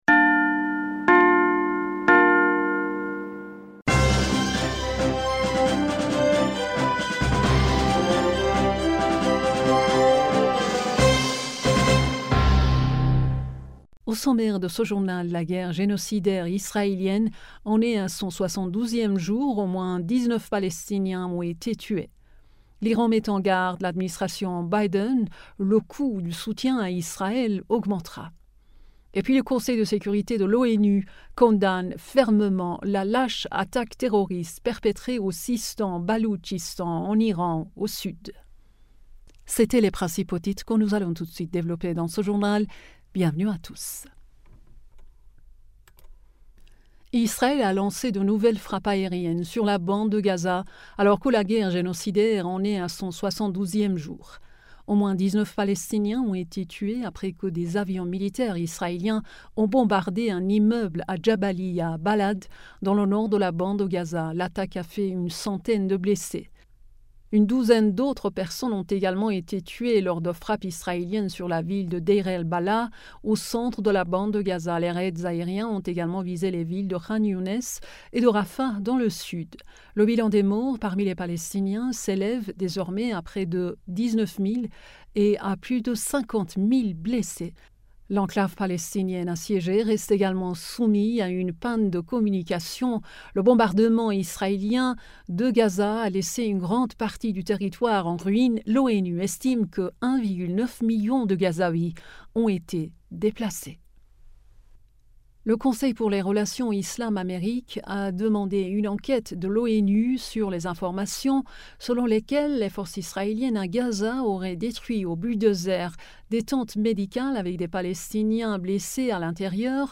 Bulletin d'information du 17 Decembre 2023